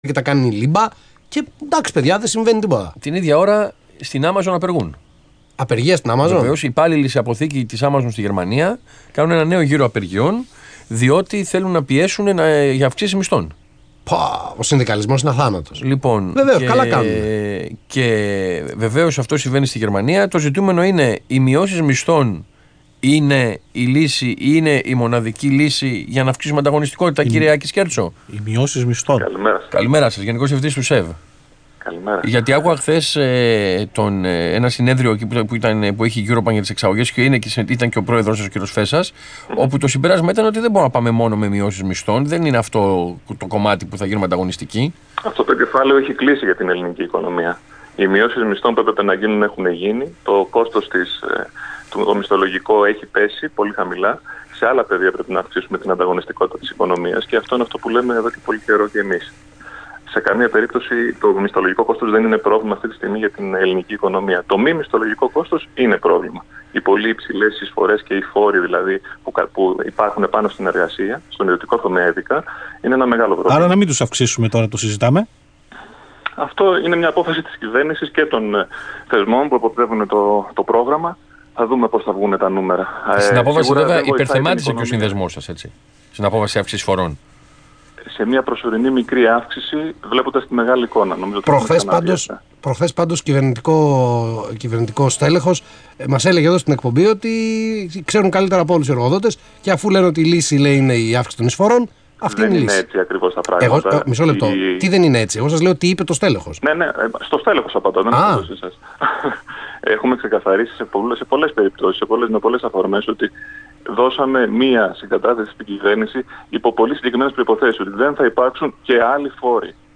Ο κ. Άκης Σκέρτσος, Γενικός Διευθυντής του ΣΕΒ στον Ρ/Σ Αθήνα 9.84, 22/3/2016